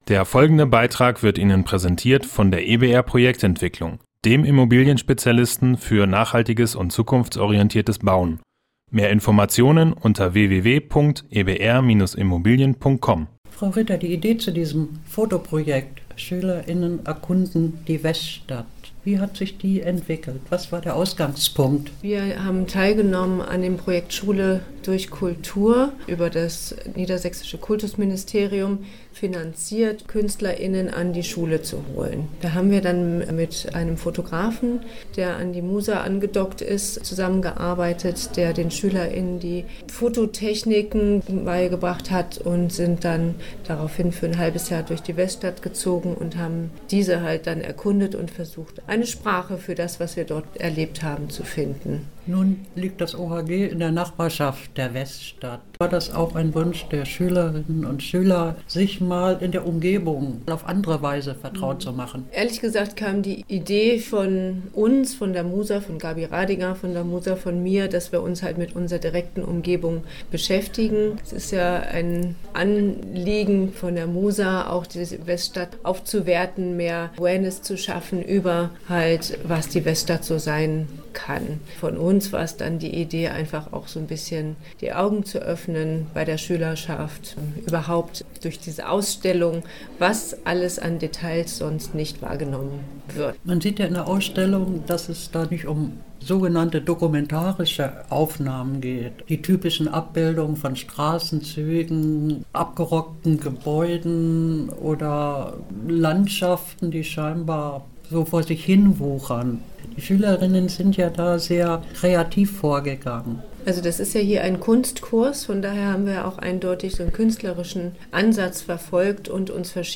Beiträge > Ausstellung „Schüler*innen des Otto-Hahn-Gymnasiums erkunden die Weststadt“ - StadtRadio Göttingen